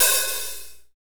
Index of /90_sSampleCDs/Northstar - Drumscapes Roland/KIT_Hip-Hop Kits/KIT_Rap Kit 1 x
HAT H H LH0E.wav